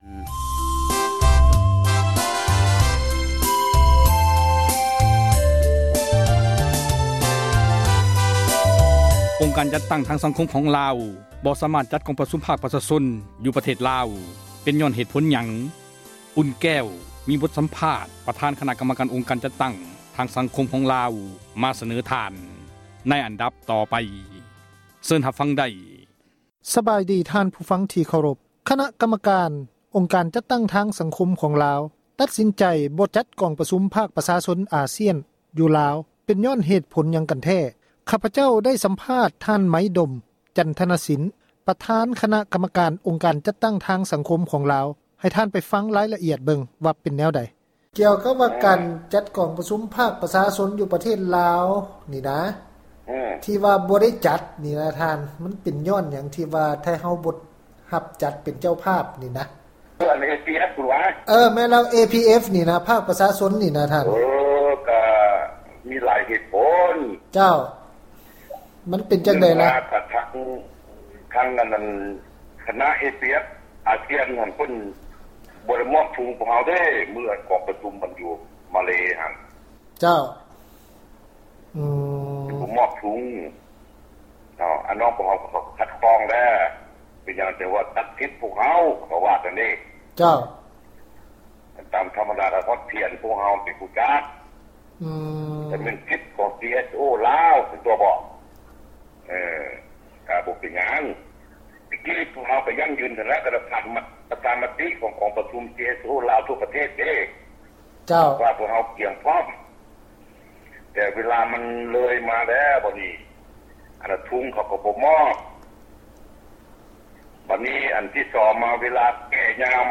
ສໍາພາດອົງການຈັດຕັ້ງທາງ ສັງຄົມ ສປປລາວ